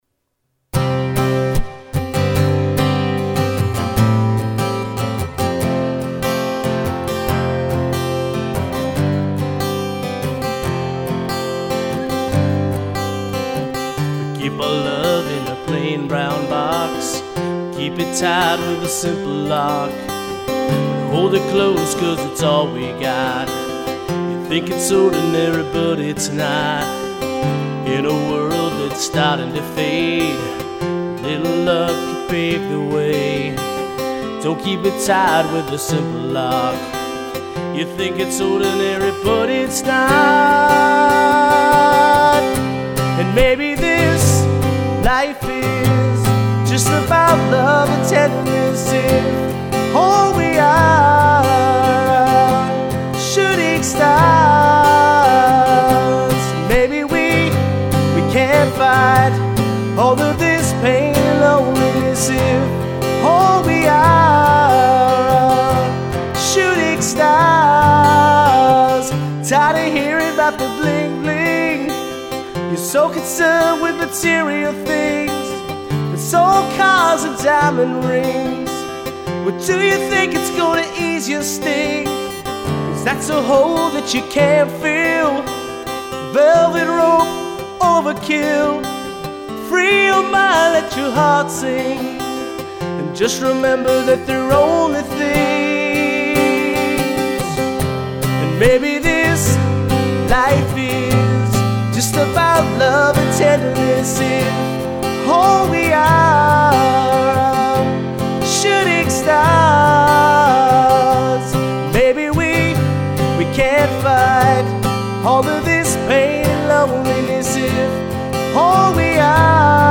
Home/Studio Recordings